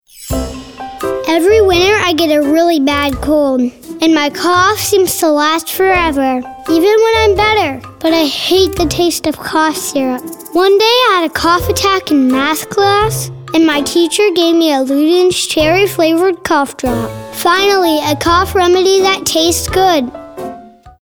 Ludens Cough Drop conversational, genuine, informative, kid-next-door, Matter of Fact, real, sincere, younger